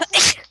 sneeze5.wav